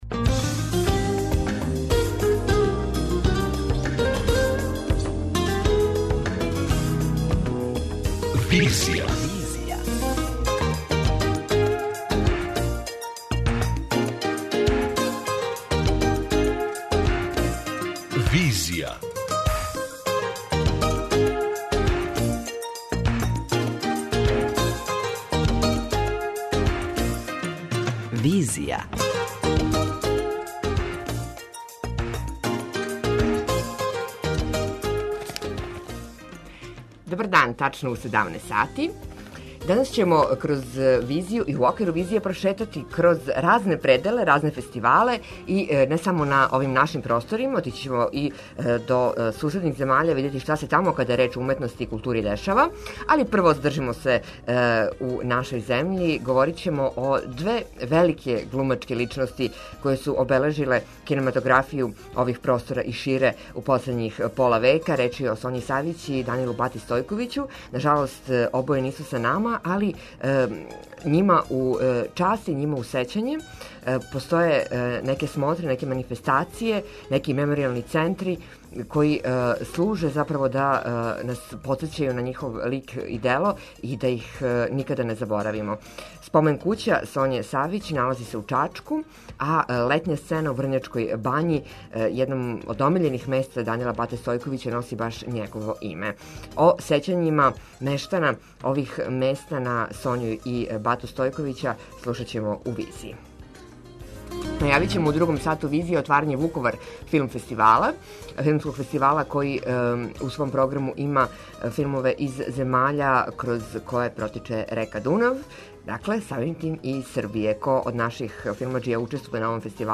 преузми : 55.63 MB Визија Autor: Београд 202 Социо-културолошки магазин, који прати савремене друштвене феномене.